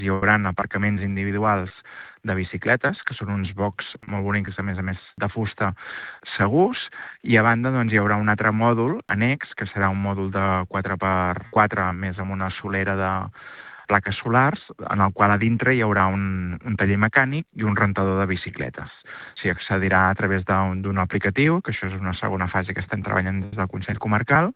El calellenc Xavier Ponsdomènech, conseller delegat de Transparència, Bon govern i Planificació estratègica, ha detallat a RCT els serveis que oferirà: